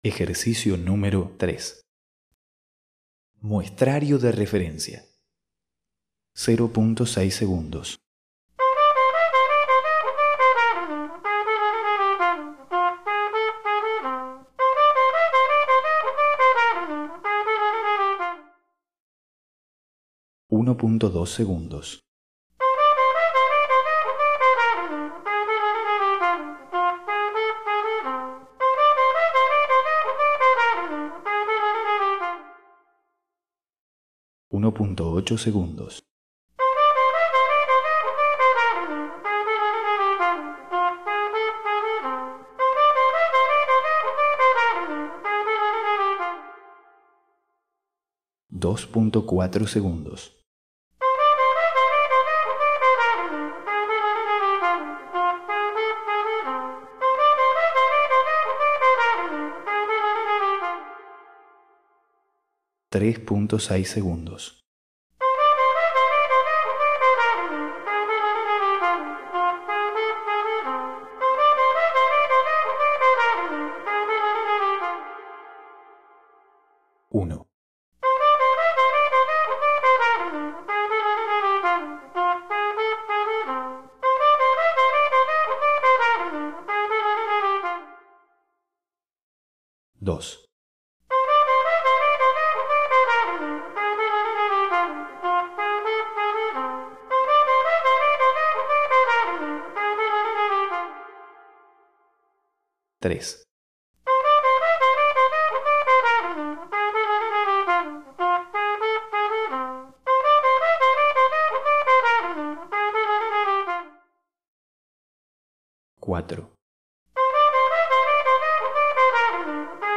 1) Reconocimiento de 5 tiempos de reverberancia
(0.6 seg; 1.2 seg; 1.8 seg; 2,4 seg y 3,6 seg)
Reverb time - Size 2.239 kb (file name ej01rev.mp3)